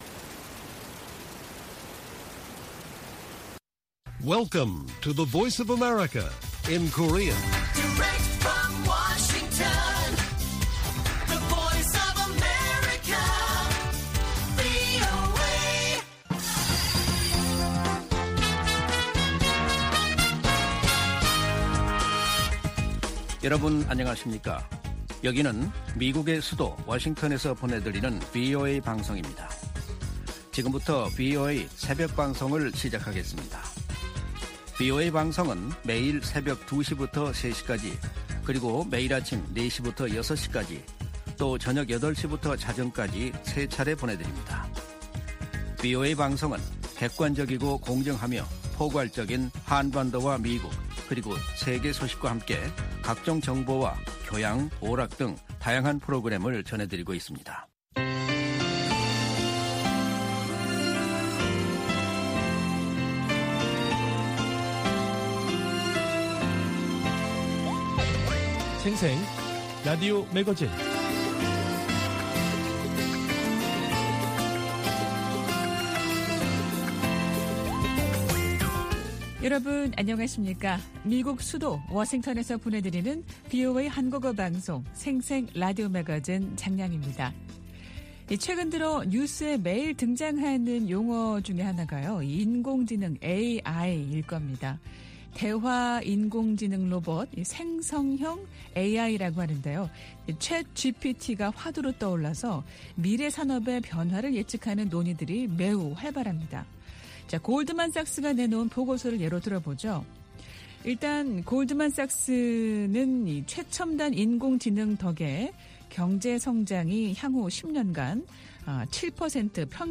VOA 한국어 방송의 월요일 새벽 방송입니다.